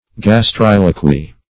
Search Result for " gastriloquy" : The Collaborative International Dictionary of English v.0.48: Gastriloquy \Gas*tril"o*quy\, n. A voice or utterance which appears to proceed from the stomach; ventriloquy.
gastriloquy.mp3